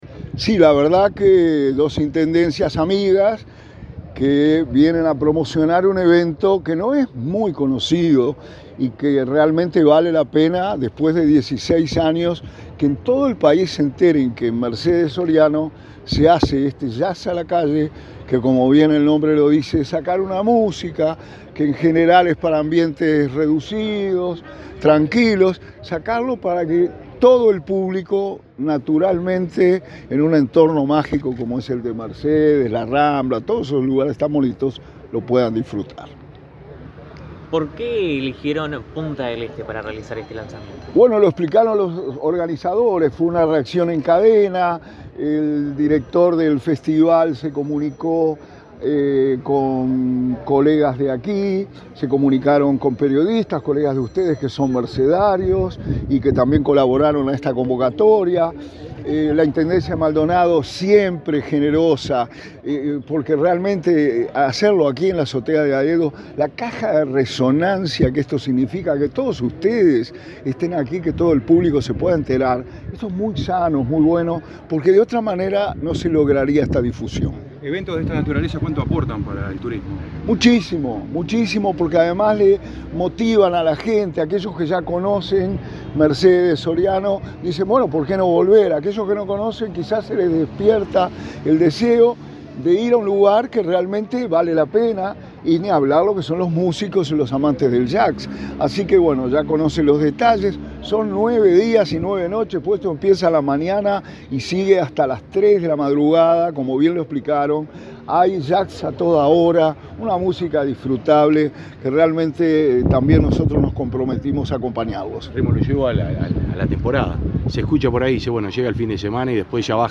Declaraciones del subsecretario de Turismo, Remo Monzeglio
El subsecretario de Turismo, Remo Monzeglio, dialogó con la prensa en Maldonado, luego de participar del lanzamiento del 16.° Encuentro de Jazz a la